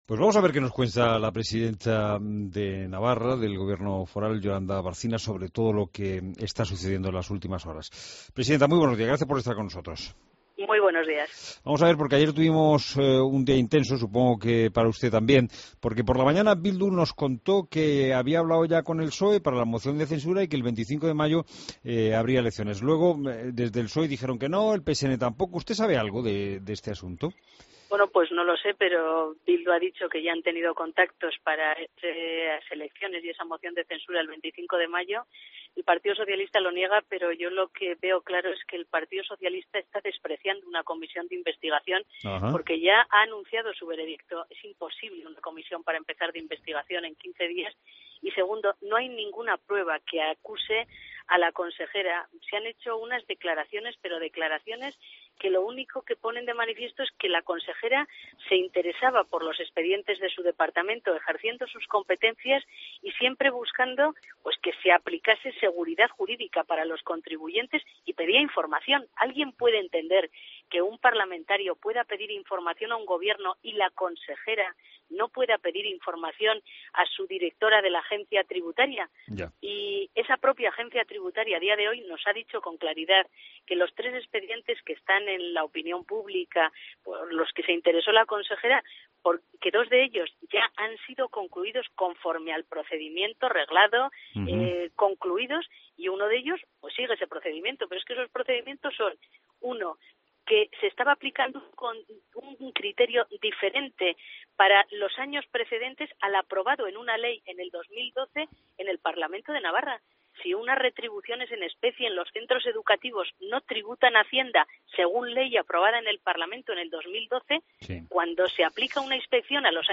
Entrevista a Yolanda Barcina en La Mañana de COPE